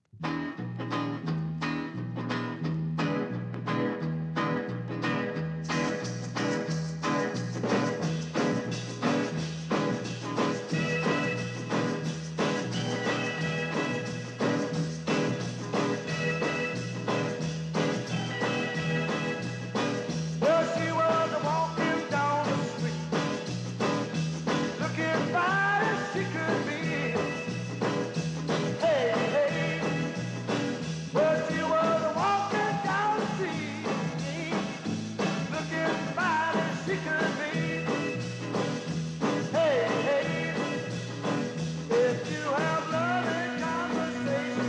Mod & R&B & Jazz & Garage